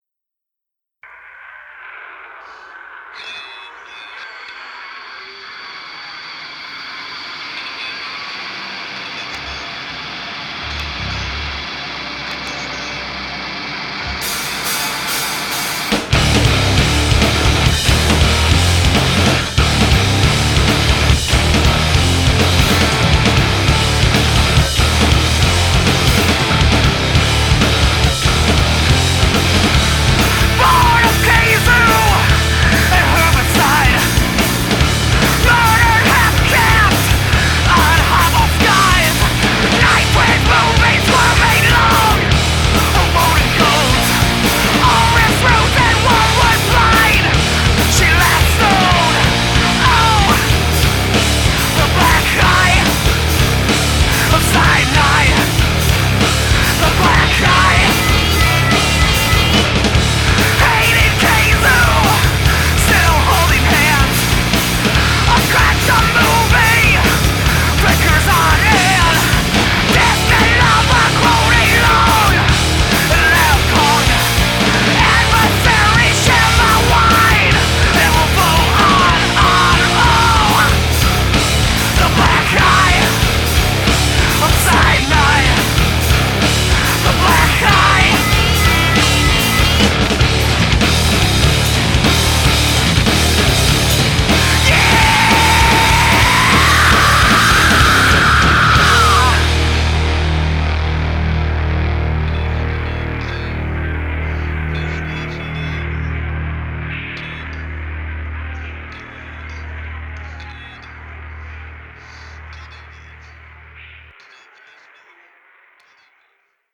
this pissed-off Indie-rock platter is mmm-good.